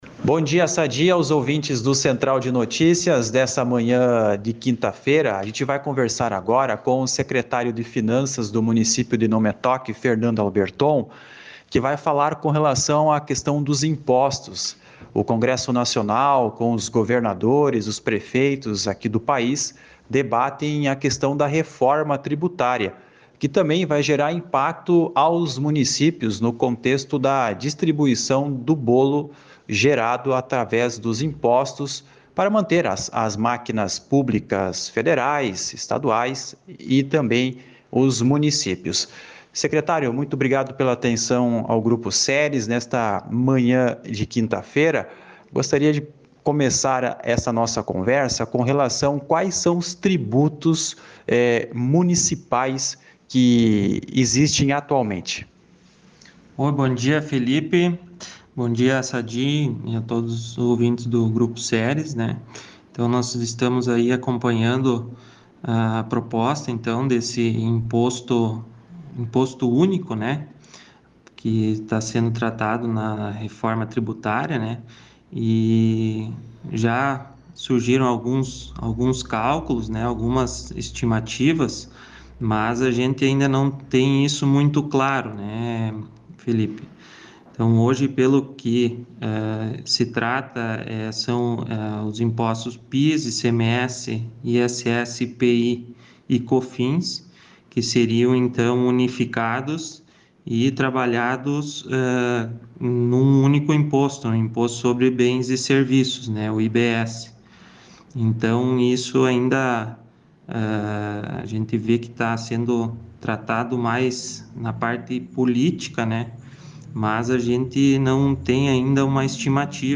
Entrevista: secretário de Finanças de Não-Me-Toque avalia possíveis mudanças com a reforma tributária » Grupo Ceres de Comunicação
O secretário de Finanças de Não-Me-Toque, Fernando Alberton, em entrevista ao Grupo Ceres falou deste cenário, da distribuição dos tributos especialmente para os municípios.
Ouça a entrevista que foi ao ar no programa Central de Notícias desta quinta-feira (6):